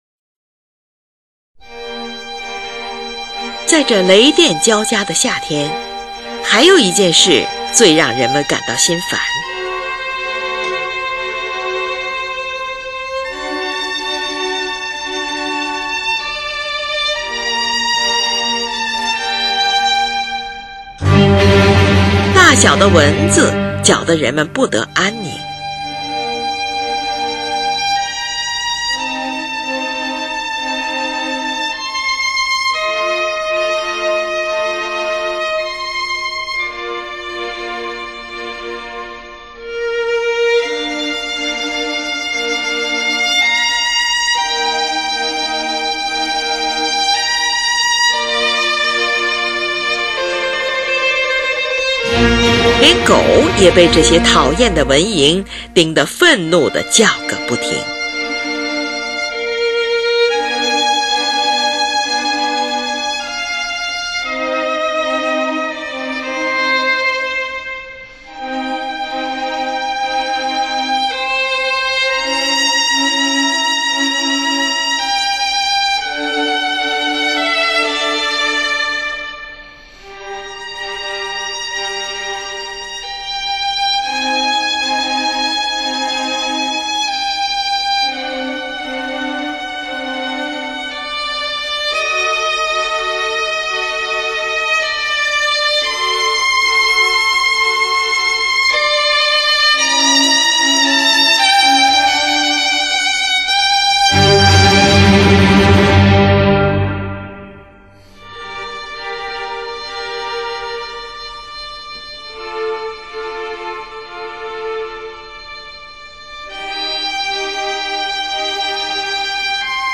小提琴协奏曲
夏天--g小调
主奏小提琴旋律在急板合奏的16分音符的中断中描写“在雷电、蚊蝇、狗叫声中，使得人们疲惫的身子不得安宁。
协奏音型用来表示大小的蚊蝇。”